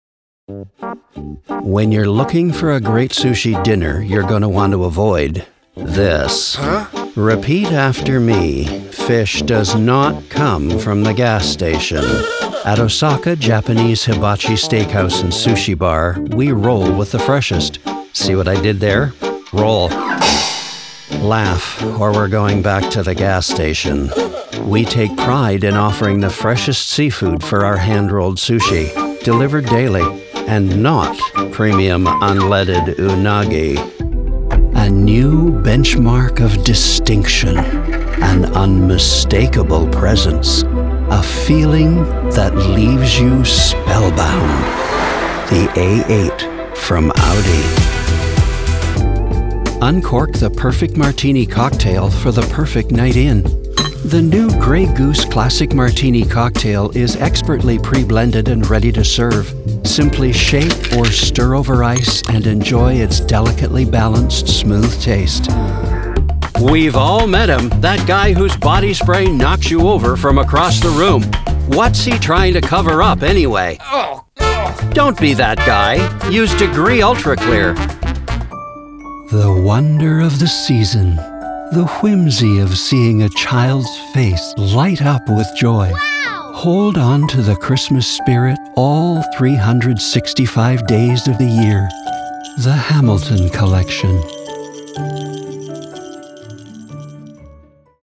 Adult (30-50) | Older Sound (50+)
0825Commercial_1.mp3